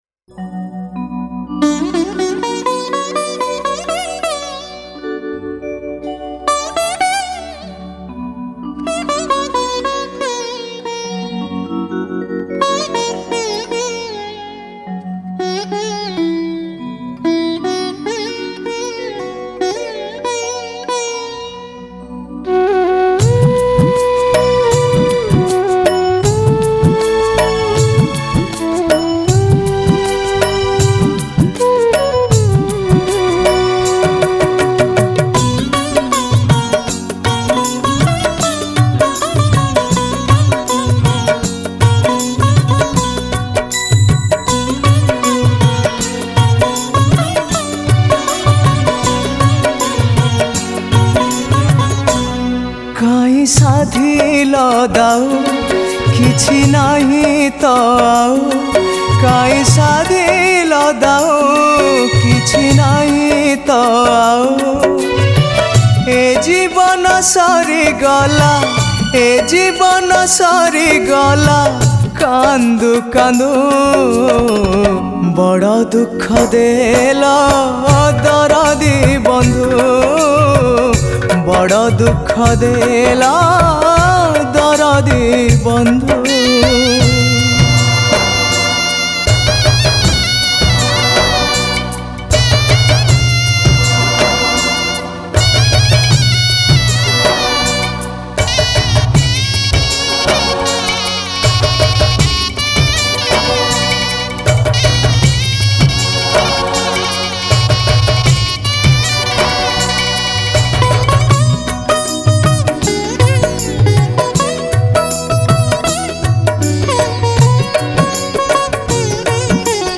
Odia Bhajan Songs